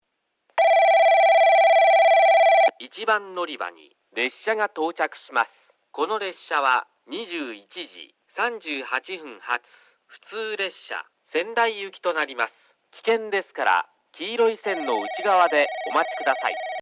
放送はJACROS簡易詳細型で、接近ベルが鳴ります。
スピーカーはFPS平面波です。なお放送の音割れが激しいですがこれは元からで、夜間音量の方が綺麗に聞こえます。
1番のりば接近放送（折り返し　川内行き）